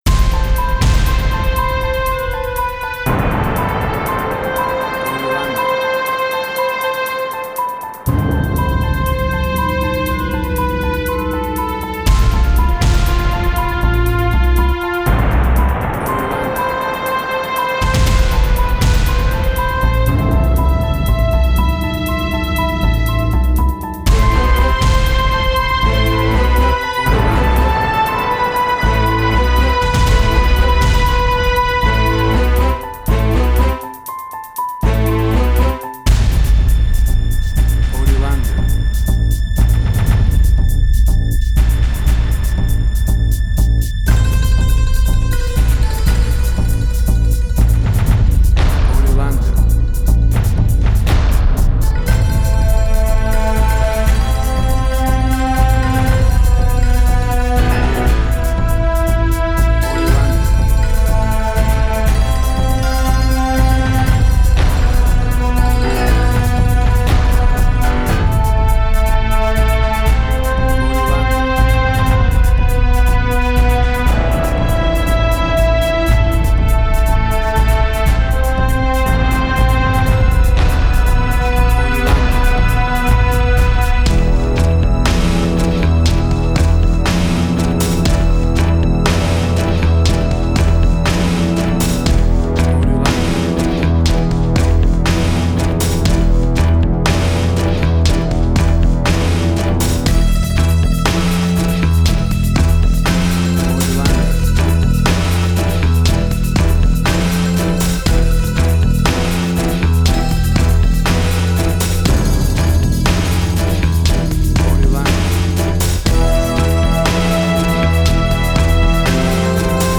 Post-Electronic.
Tempo (BPM): 60